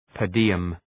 Προφορά
{pər’dıəm}